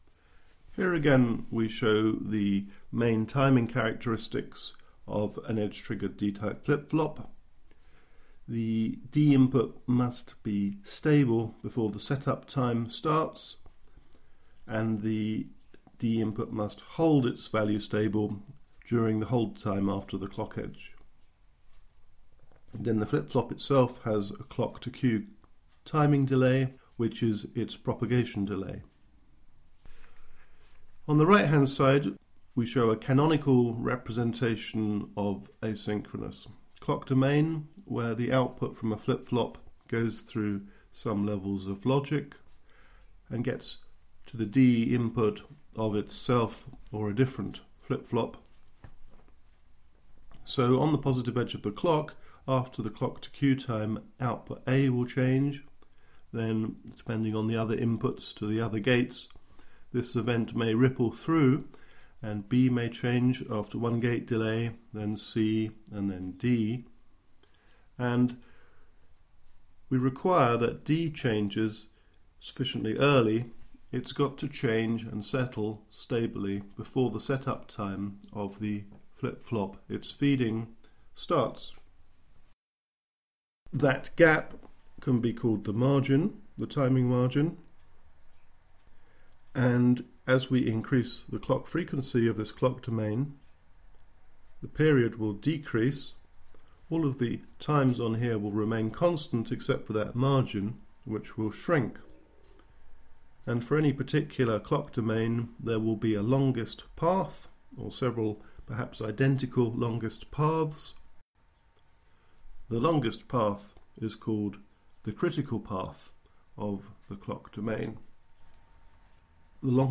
critical_path_timing_delay.commentry.mp3